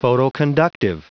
Prononciation du mot photoconductive en anglais (fichier audio)
photoconductive.wav